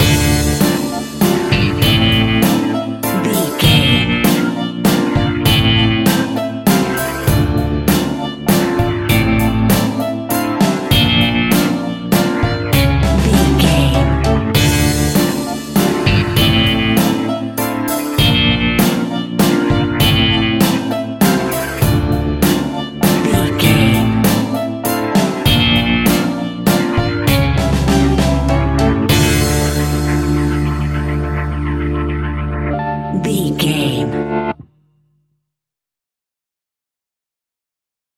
Aeolian/Minor
ominous
dark
haunting
eerie
electric guitar
violin
piano
strings
bass guitar
drums
percussion
horror music